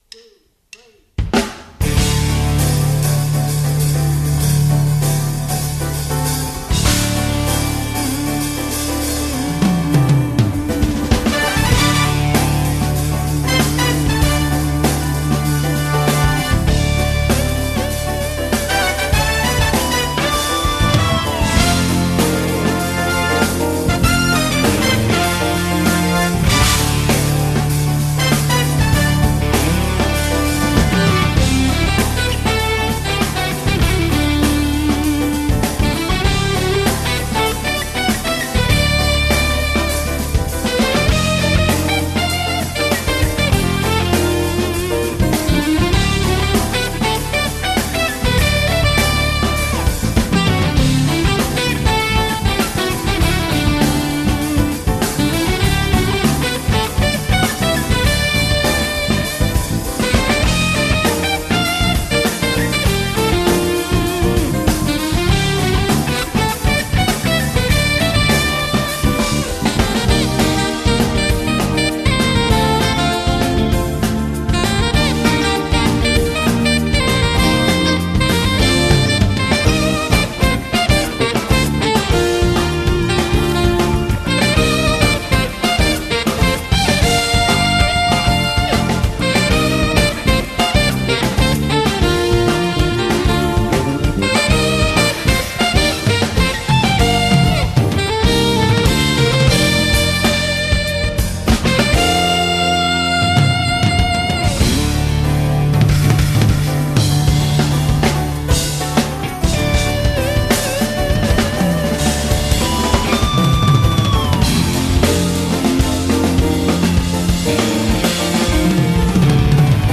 session live